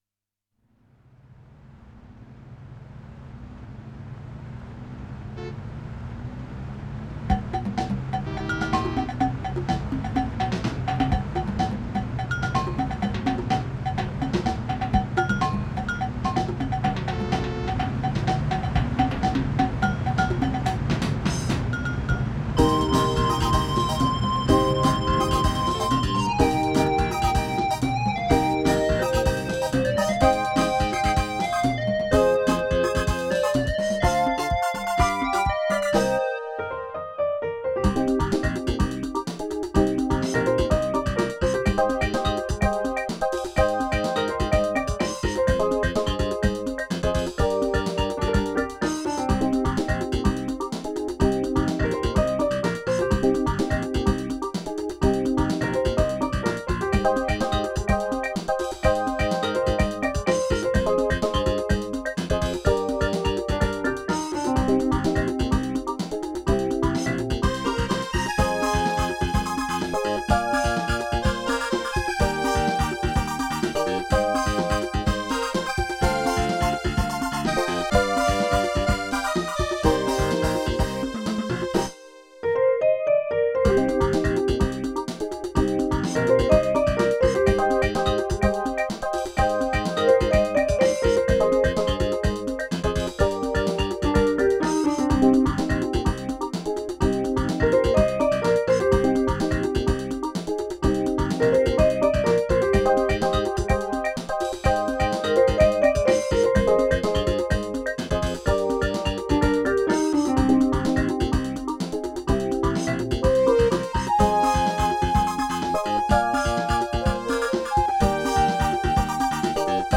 Le CSM-1 est la version expandeur du Casio CT-460.
Ses caractéristiques techniques sont assez limitées mais impressionnantes pour l’époque: 4 canaux Midi, 16 notes de polyphonie, 28 Patchs, 52 sons de percussions, 23 sons d’effets spéciaux et une boite à rythme intégrée.